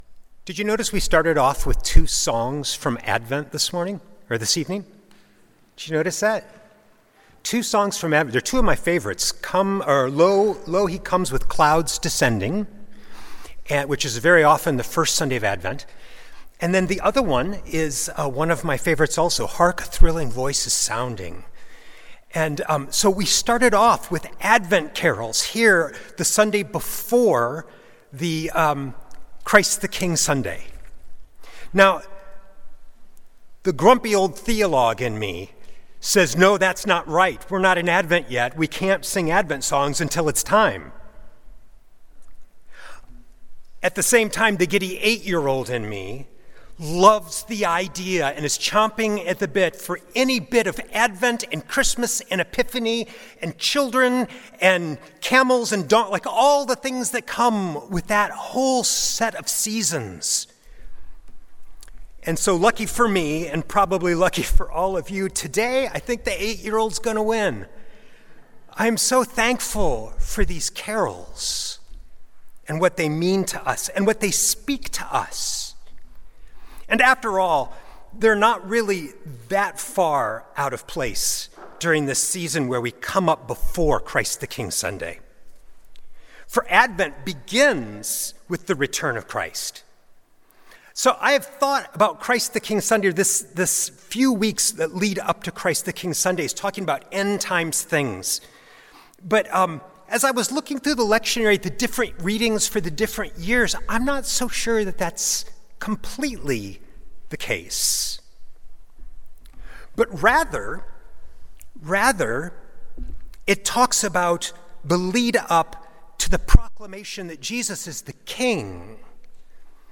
Sunday Worship–Nov. 16, 2025
Sermons